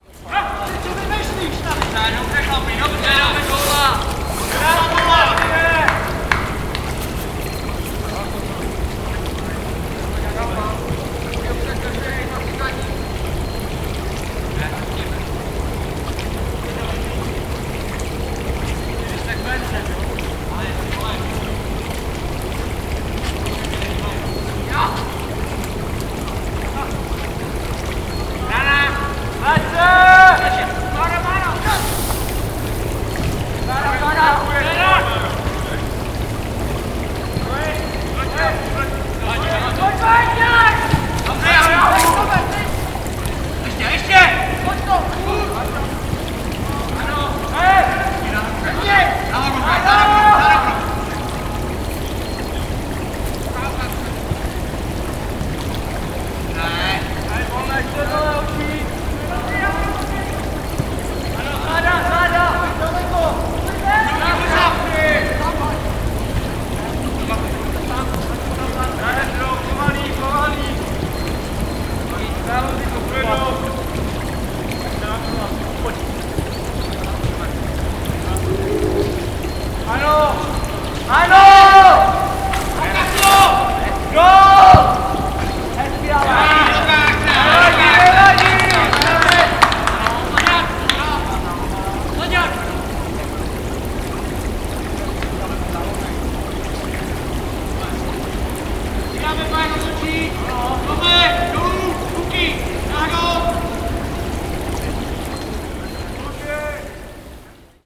field recordings, sound art, radio, sound walks
Lesní fotbalisté v Uhříněvsi
Tagy: sport periferie voda zahrada
Vedle obory je také fotbalový areál SK Čechie,kde v neděli trénovalo místní mužstvo.